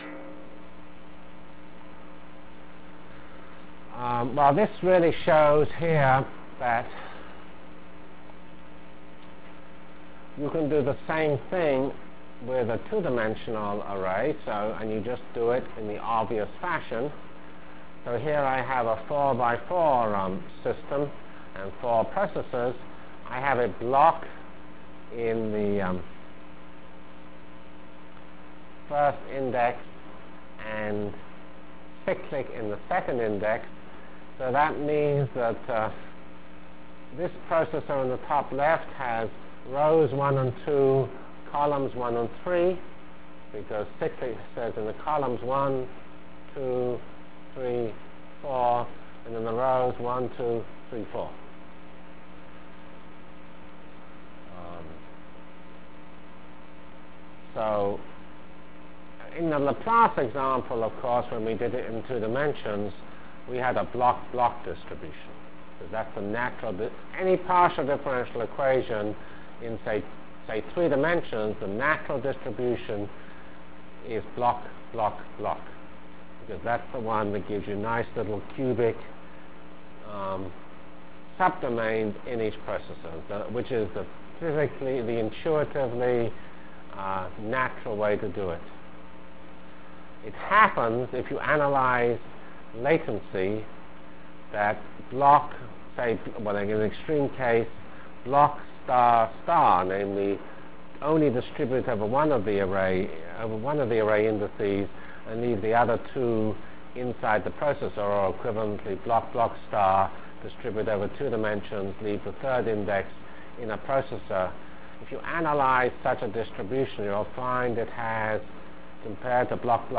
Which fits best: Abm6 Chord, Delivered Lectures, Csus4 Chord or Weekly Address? Delivered Lectures